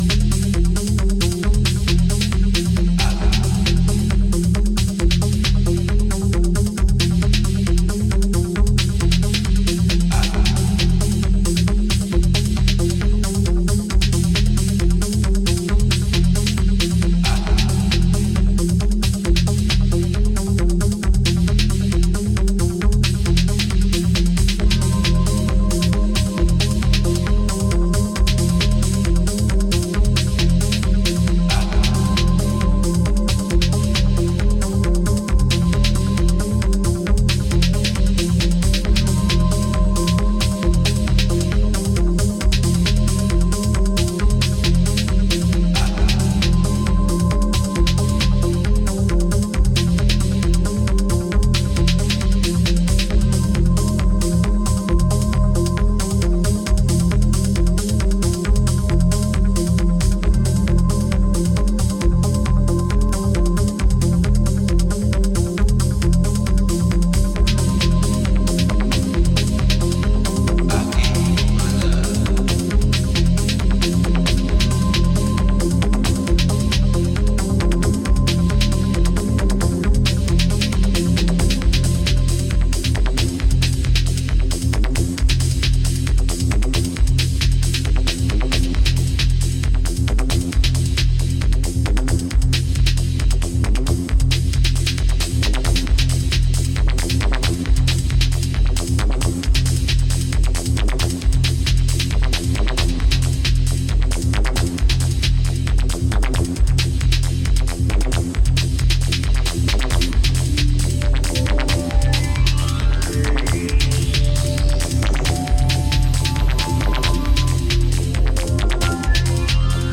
Used Electro Techno